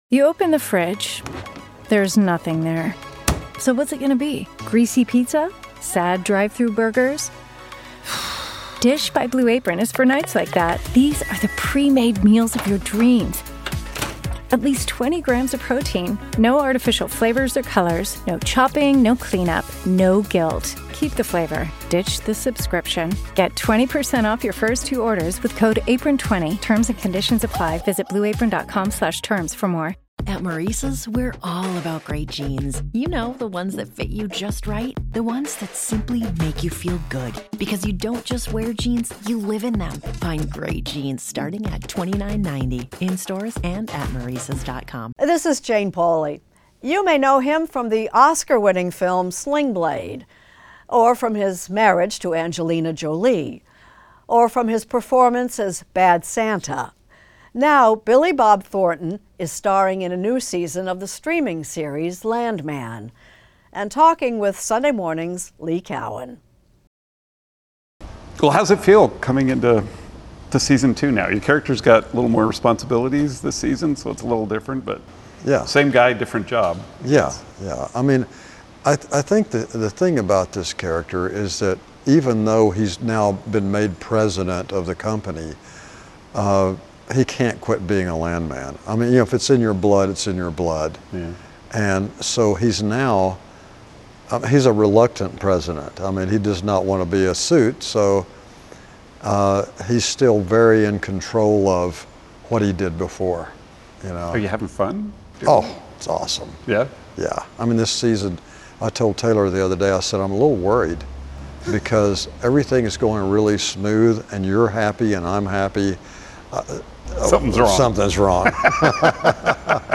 Extended Interview: Billy Bob Thornton
Oscar-winning actor-writer Billy Bob Thornton talks with Lee Cowan about returning to his character Tommy Norris in the Paramount+ series "Landman," set in the oil fields of West Texas. He also discusses his rock band, The Boxmasters; his relationship to fame; and how Hollywood legend Billy Wilder changed his career.